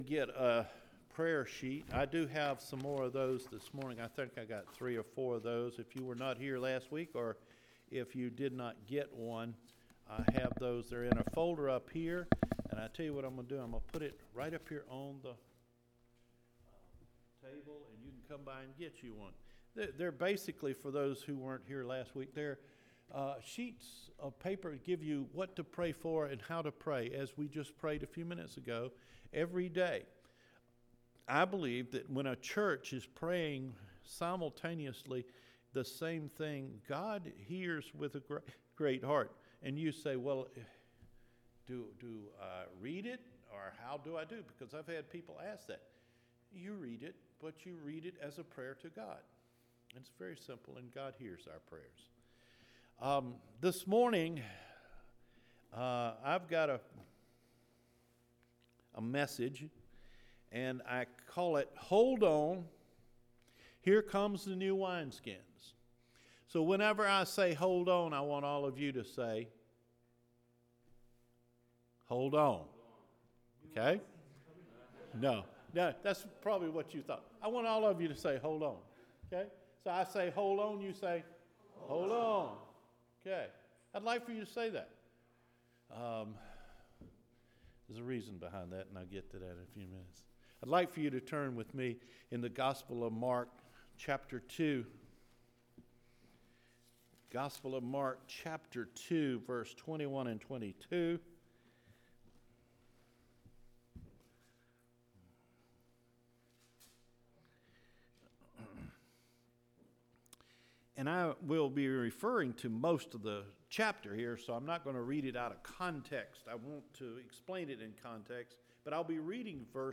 HOLD ON! NEW WINESKINS ARE COMING – JANUARY 12 SERMON